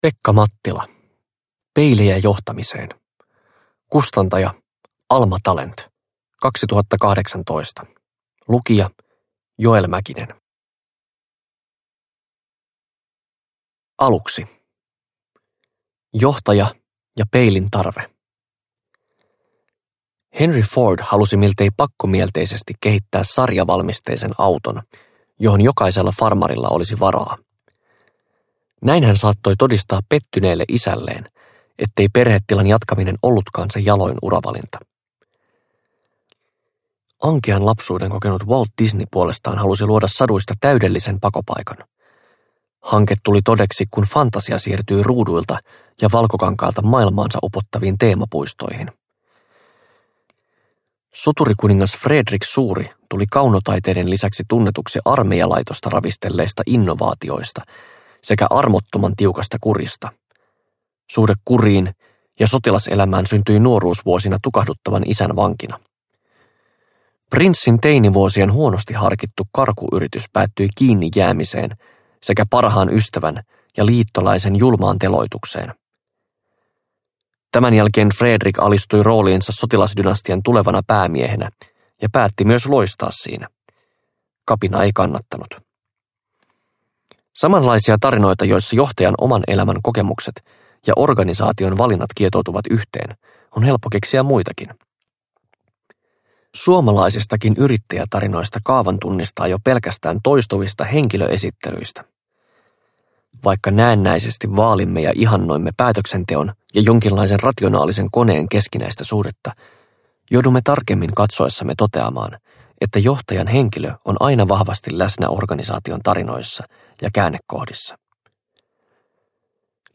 Peilejä johtamiseen – Ljudbok – Laddas ner